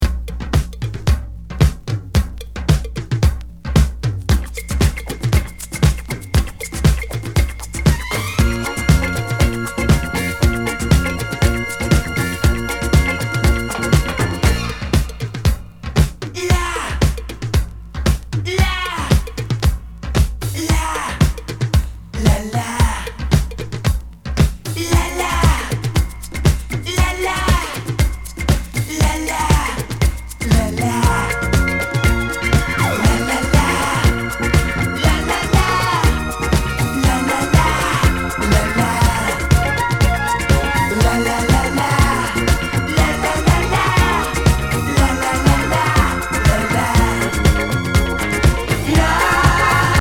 ハネたアフロ・ディスコなイントロが◎